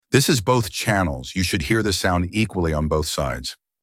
BOTH CHANNEL